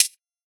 Index of /musicradar/ultimate-hihat-samples/Hits/ElectroHat B
UHH_ElectroHatB_Hit-07.wav